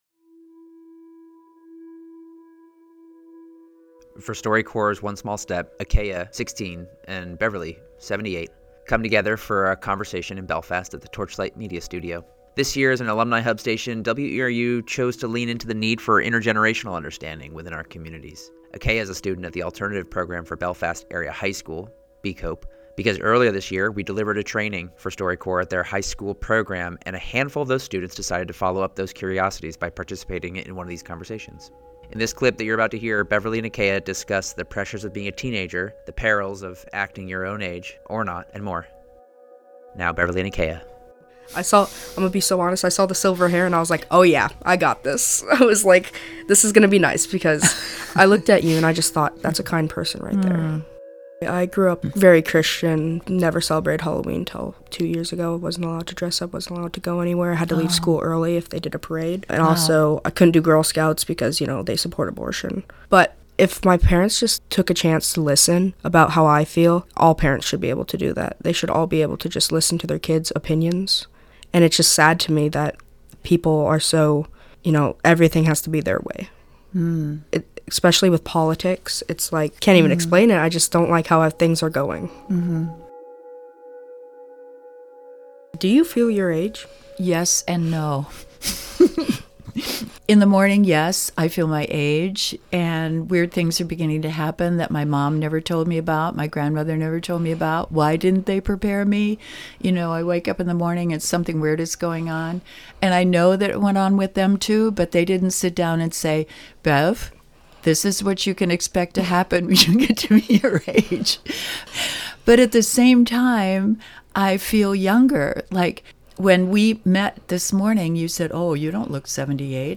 from 4–5 p.m. Participants and community members joined the conversation by phone, and joined in discussing experiences with the project, personal takeaways, and how the project has affected them since.